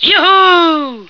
flak_m/sounds/male1/est/M1yeehaw.ogg at 86e4571f7d968cc283817f5db8ed1df173ad3393
M1yeehaw.ogg